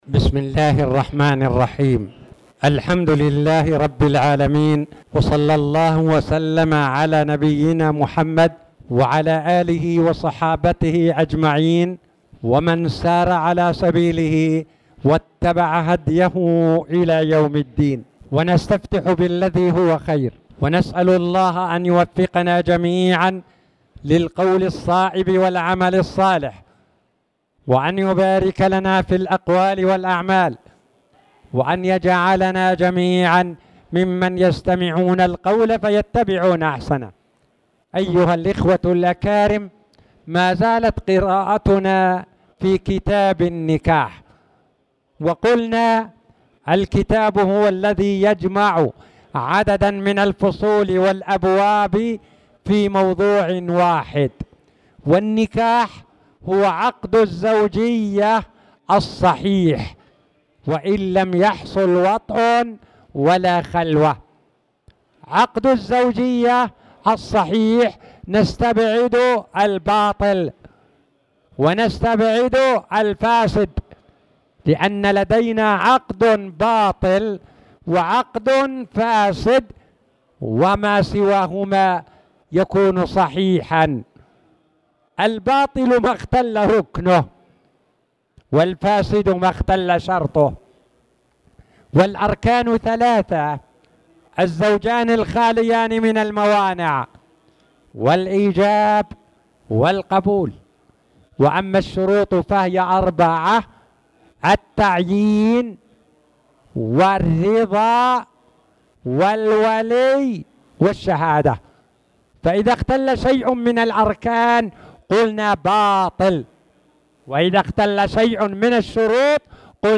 تاريخ النشر ٢٧ ربيع الثاني ١٤٣٨ هـ المكان: المسجد الحرام الشيخ